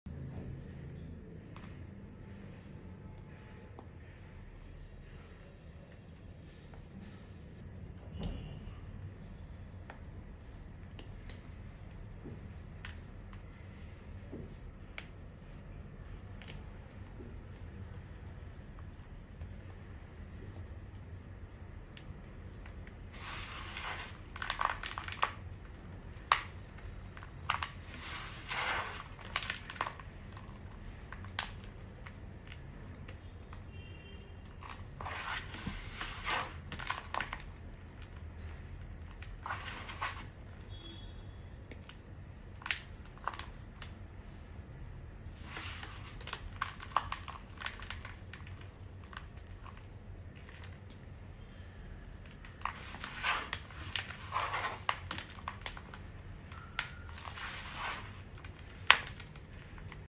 Gym chalk crush sound effects free download